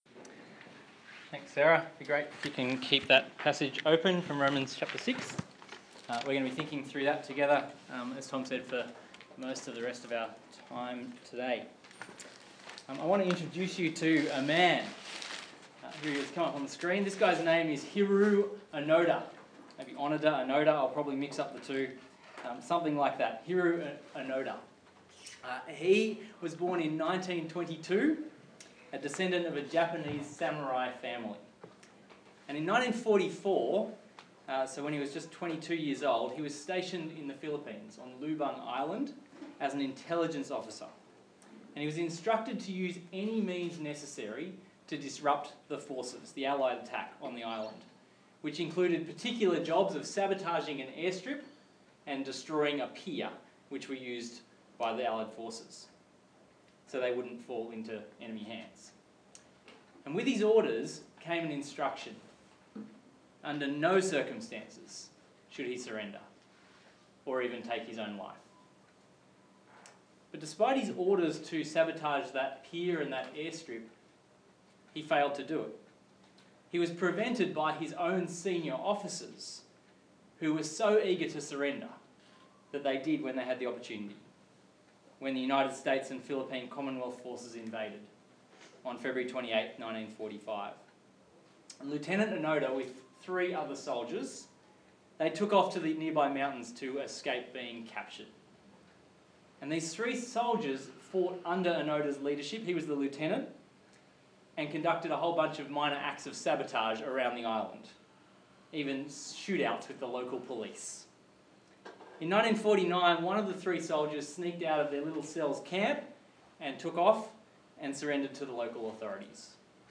Gutter to Glory Passage: Romans 6:1-23 Talk Type: Bible Talk